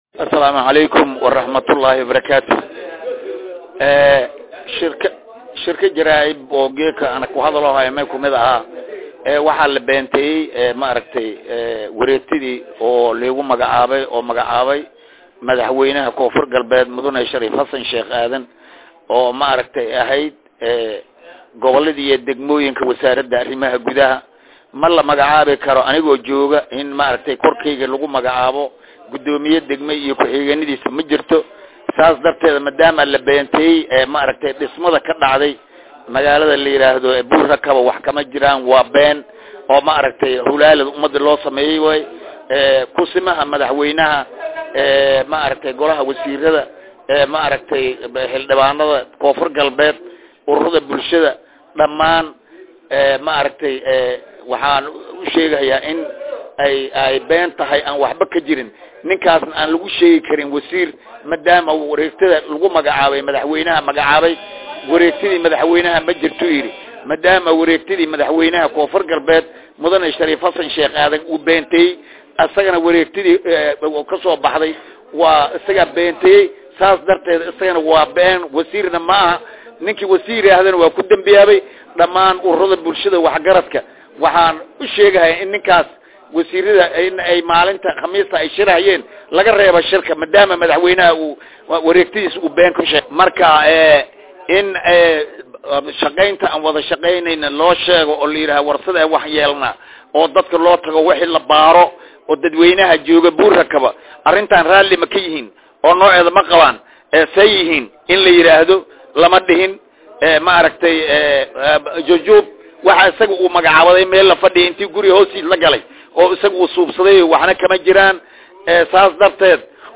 Baydhabo(INO)-  Waxaa Maanta Shir Jaraa’id Ku Qabtey Magaalada Baydhabo Ee Xarunta Gobolka Bay, agaasimaha Gobolada Koonfur Galbeed Soomaliya, Maxamed Cali Aaden ( Qalinle),isagoona Si kulul ugu hadley Wareegto Shaley Ka Soo Baxdey Xafiiska Wasiirka Wasaaradda Arimaha Gudaha iyo Dowlaha Hoose C/raxmaan Ibraahim Aden (Sate)  taasoo isla markaana Maamul Cusub looga Magacaawey Degmada Buurhakaba Ee gobolka Bay.